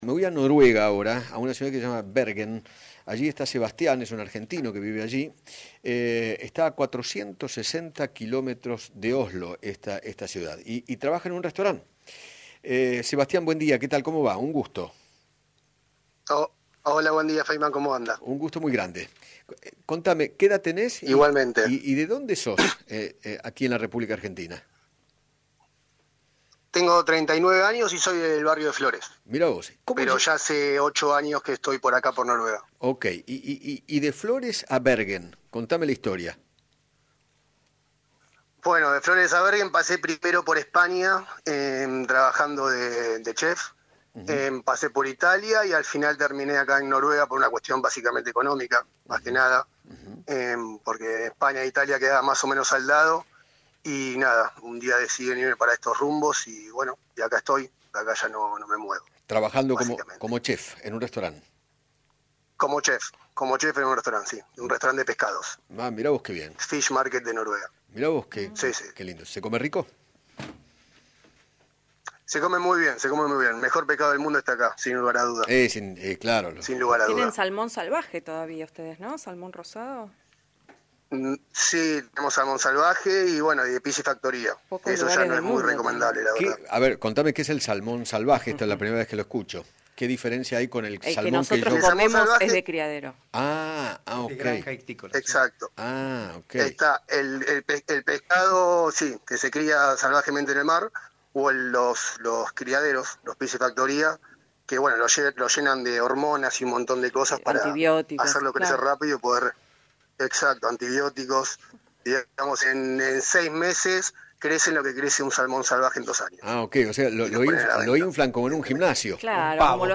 dialogó con Eduardo Feinmann sobre cómo se vive la pandemia en ese país